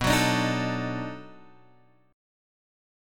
B 7th Sharp 9th